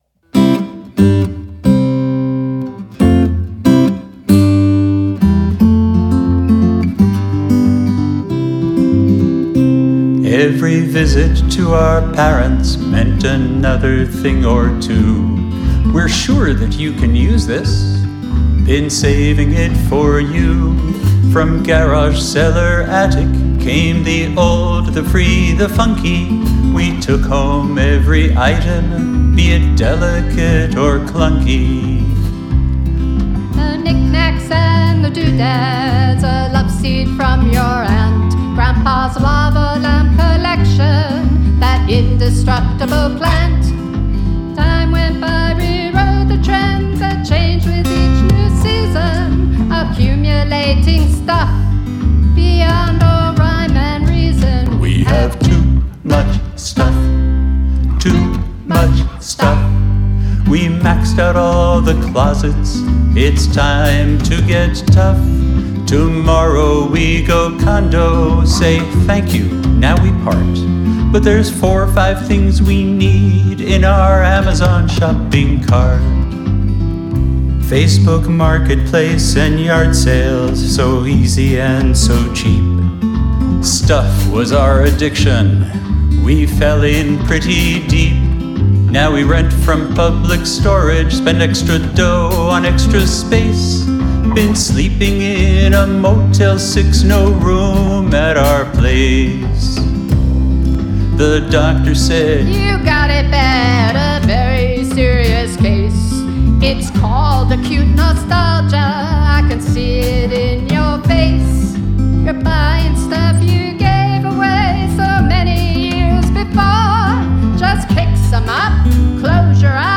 vocals, guitar, kazoo
bass, keyboards, mandolin
drums, djembe, goat hooves, tambourine